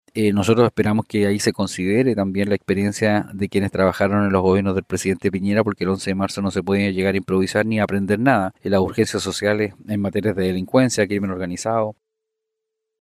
Por otro lado, desde RN, el diputado Frank Sauerbaum enfatizó que —desde su postura— se debe considerar en estas designaciones a quienes ya fueron parte del gobierno de Piñera, ya que no hay espacio para improvisaciones a partir del 11 de marzo.